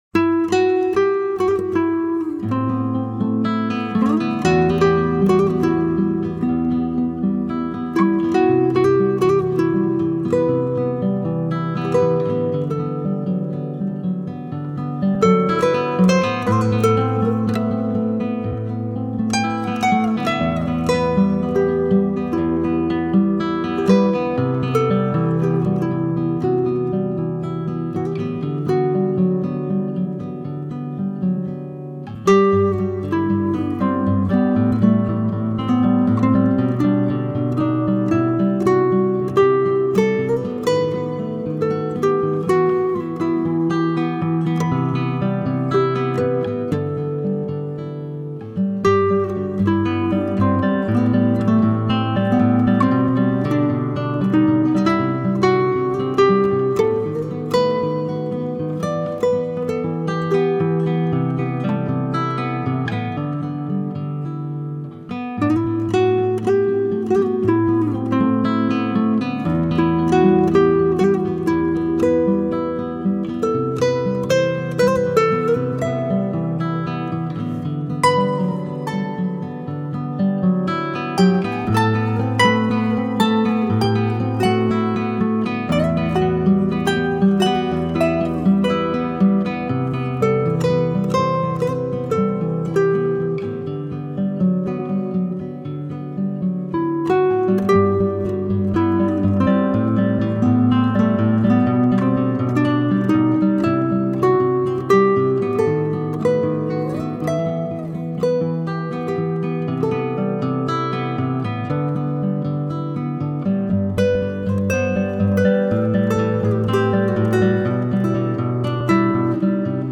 موسیقی بی کلام , آرامش بخش , عصر جدید , گیتار